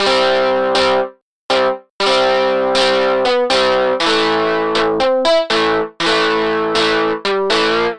synth guitar
Aj na subtractore sa da nagenerovat umela gitara pri trochu snazeni sa, hoc to znie furt velmi umelo a velmi nie verne...ale nic lepsie synteticke som nepocul :-)
synth_guitar_dist_02.mp3